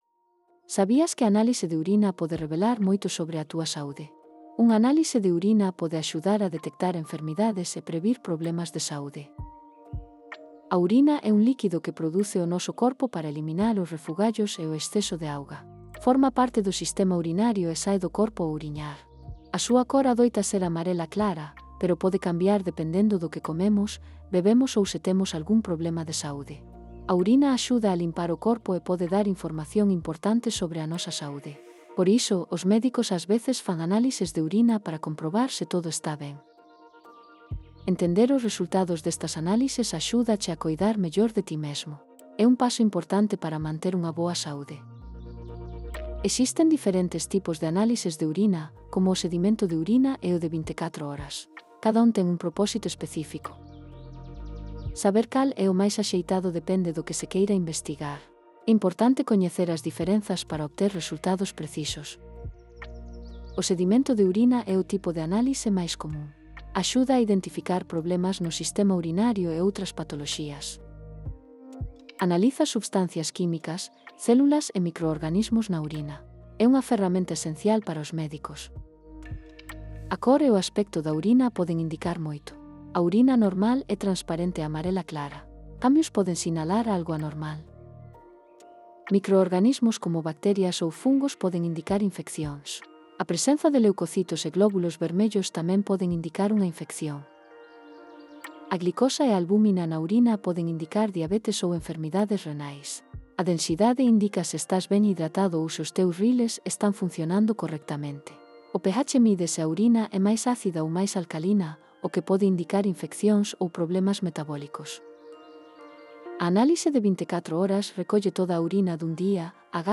Elaboración propia coa ferramenta Synthesia (CC BY-SA)